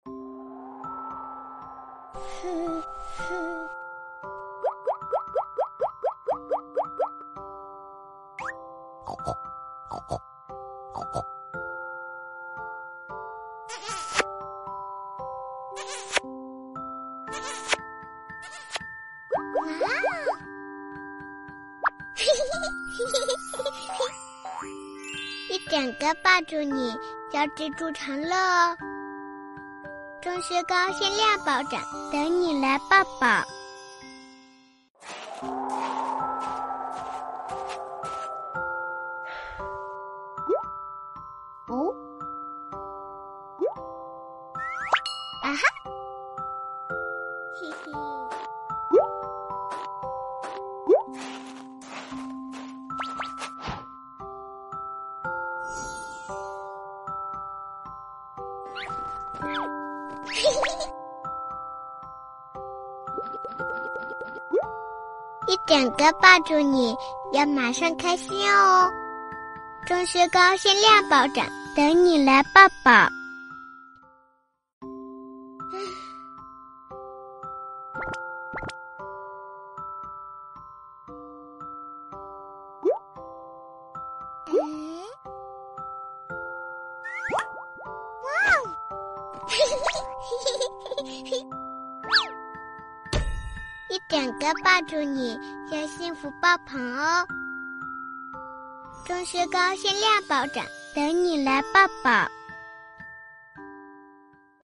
女267-童声 钟薛高
女267专题广告解说彩铃 v267
女267-童声----钟薛高.mp3